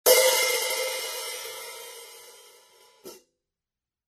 Zildjian 14" A Mastersound Hi-Hat Cymbals
The Zildjian 14 A Mastersound Hi-Hat Cymbals feature the same crisp, rich and colorful sounds associated with the A Custom range, but with a bolder and full-bodied overall sound.
14" A Zildjian Mastersound HiHat (Pair) - A0123 14" A Zildjian Mastersound HiHat (Pair) - Product Spotlight The Zildjian Mastersound HiHats have a hammered outer edge on the bottom HiHat that creates a radical, clean, fast "chick."